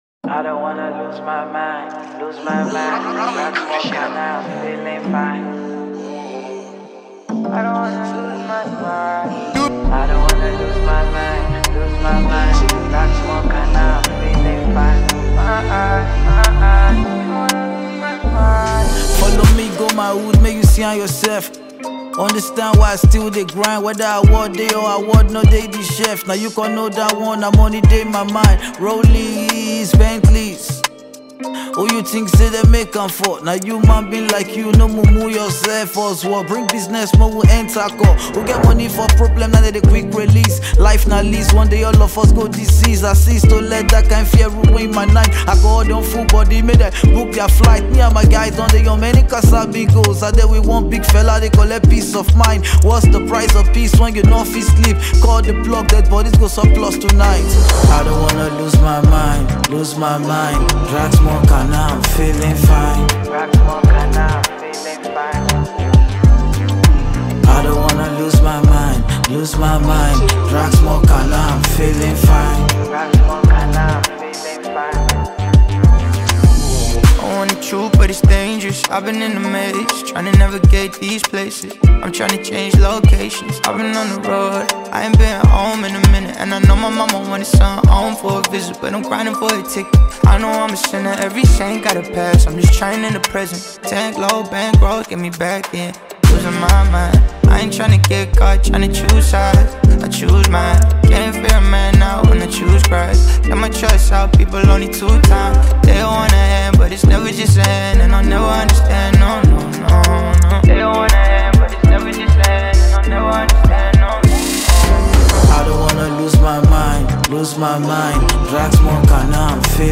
Talented Nigerian rapper